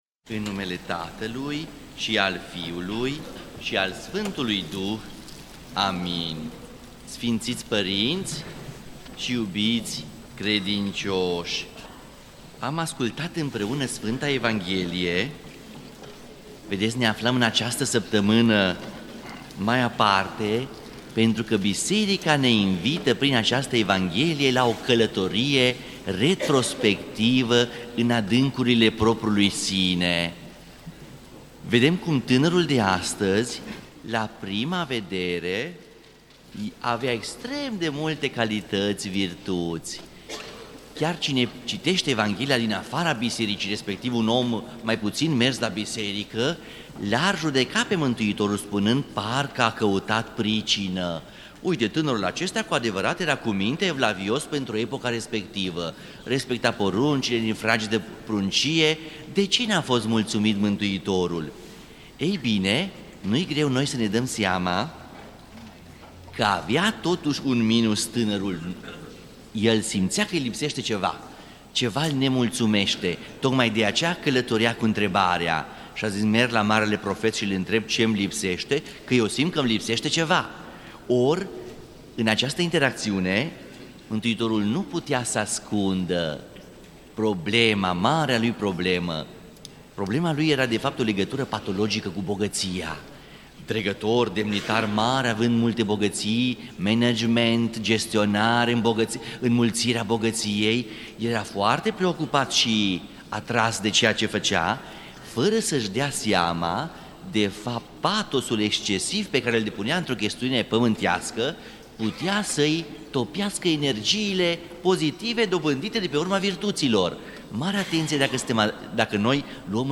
Predică la Duminica a 30-a după Rusalii (Dregătorul bogat - păzirea poruncilor)
rostit în Catedrala Mitropolitană din Cluj-Napoca